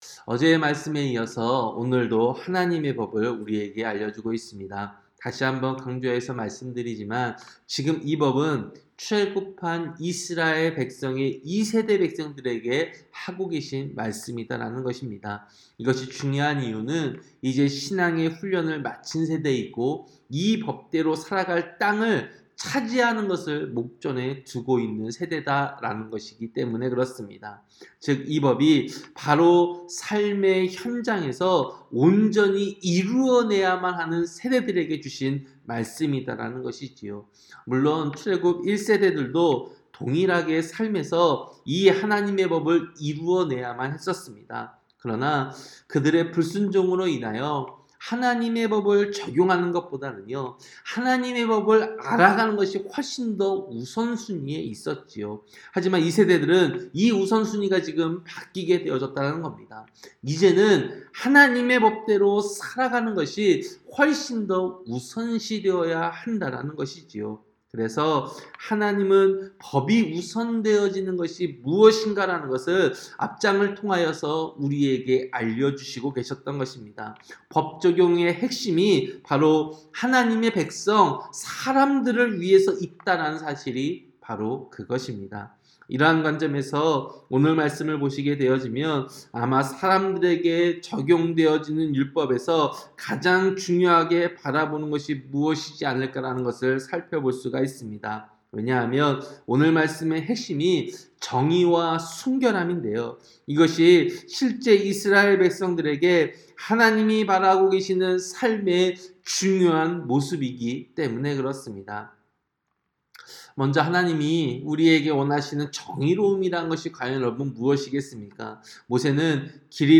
새벽설교-신명기 22장